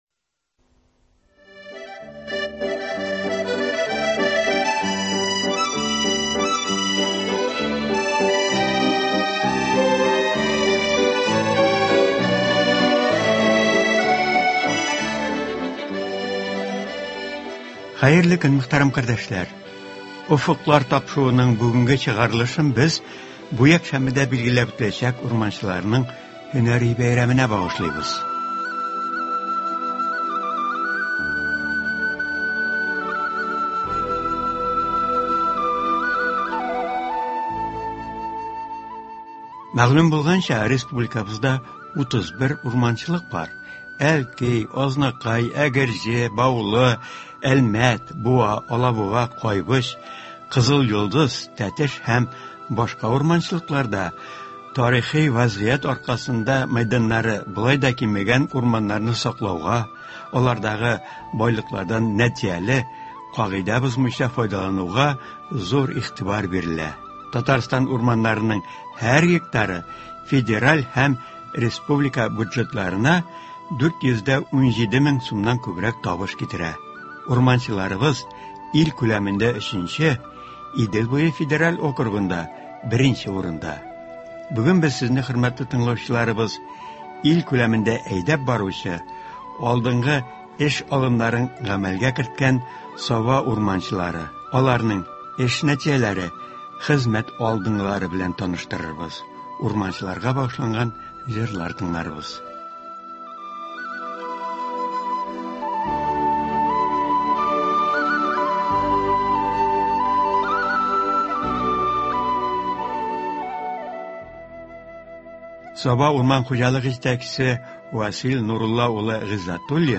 Бу якшәмбедә урман хуҗалыгы эшчәннәре һөнәри бәйрәмнәрен билгеләп үтәчәкләр. Саба урман хуҗалыгы эшчәннәренә багышланган информацион-музыкаль тапшыруда хезмәт ветераннары, озак еллар эшләгән урманчылар катнаша, популяр җырчылар катнашында күңелгә ятышлы җырлар яңгырый.